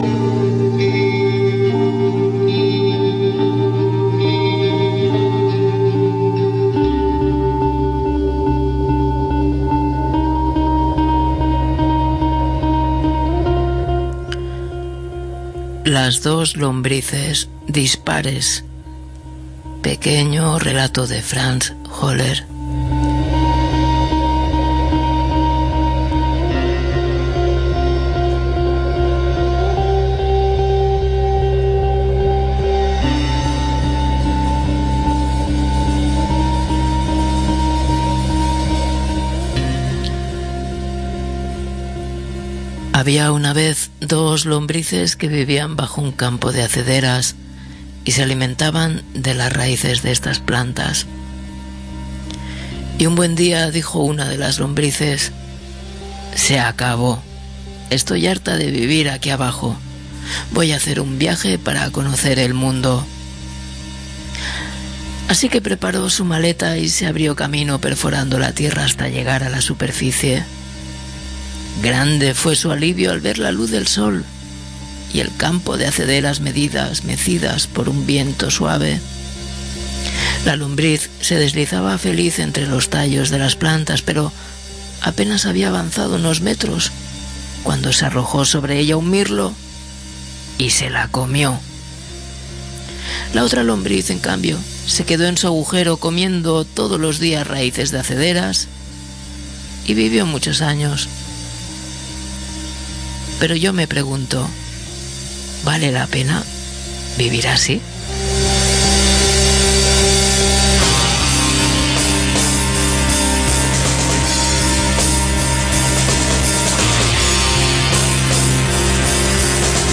Bueno, amigas y amigos, en esta tarde nos hemos envuelto en una sucesión de estropicios e incidentes que ha hecho que no hayamos podido conservar la primera mitad del programa.